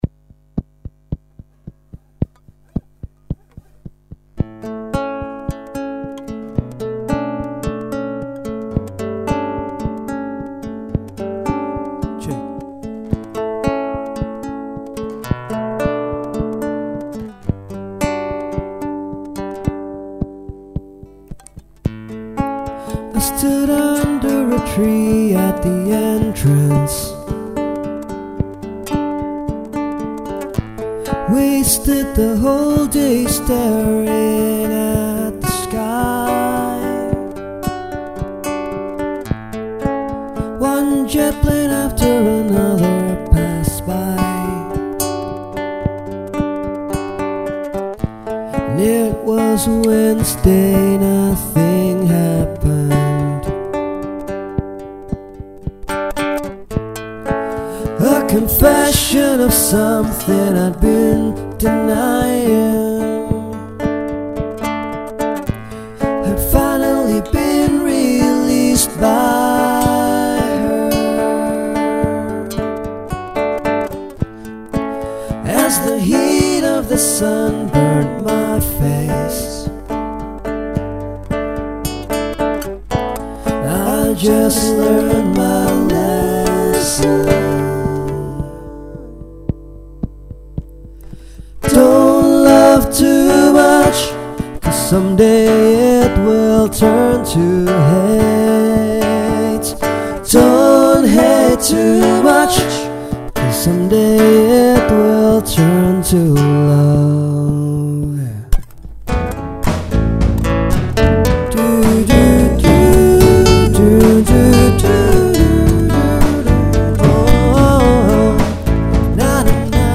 recorded at Dubai Marina